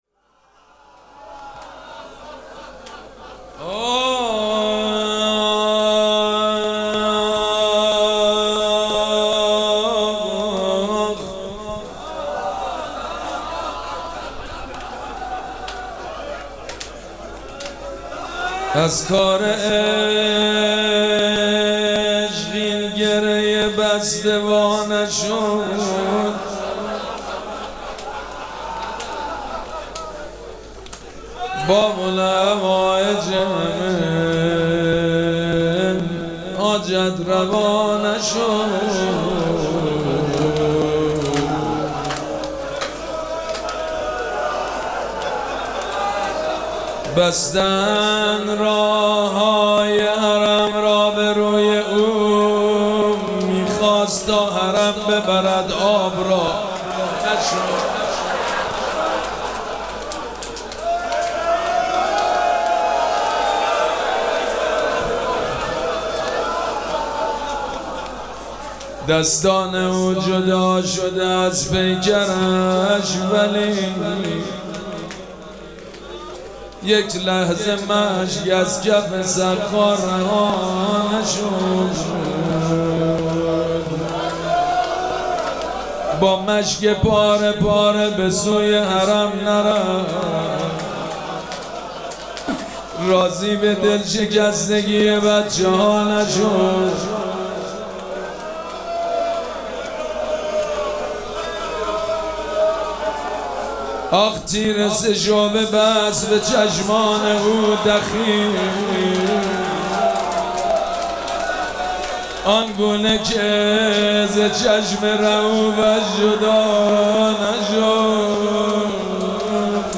مراسم عزاداری شب نهم محرم الحرام ۱۴۴۷
روضه
حاج سید مجید بنی فاطمه